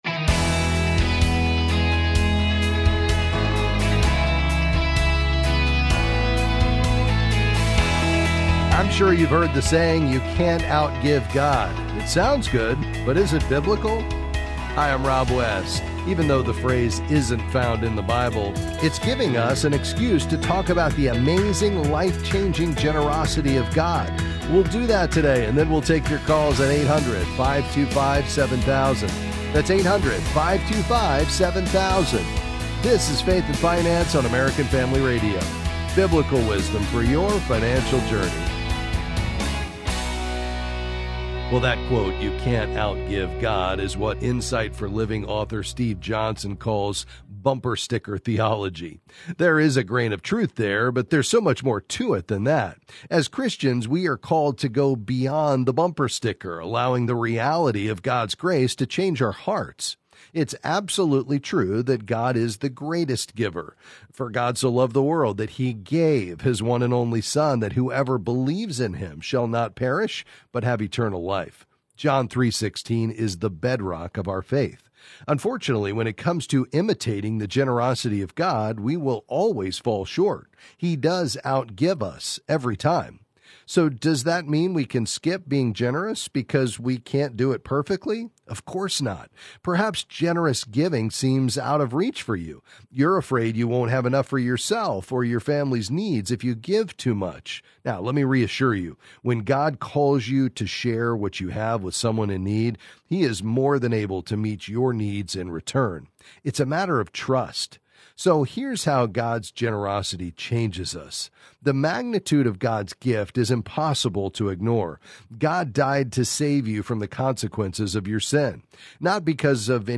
Then he welcomes your calls and financial questions.